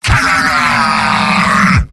Giant Robot lines from MvM. This is an audio clip from the game Team Fortress 2 .
Demoman_mvm_m_battlecry04.mp3